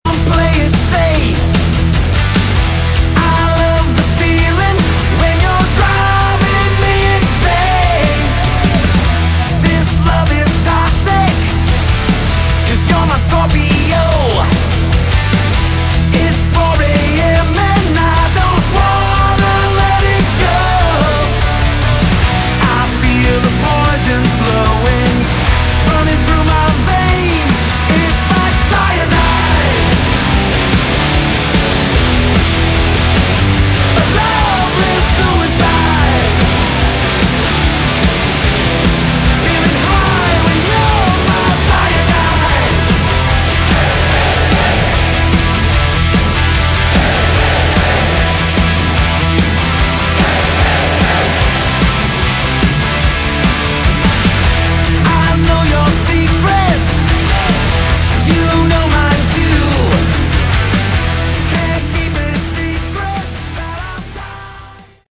Category: Melodic Rock
lead & backing vocals
guitar
guitars, keyboards
bass, backing vocals
drums